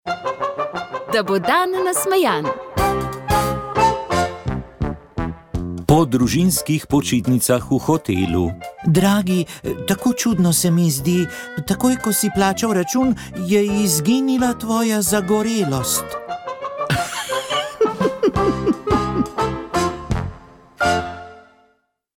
Molile so redovnice - Šolske sestre de Notre Dame.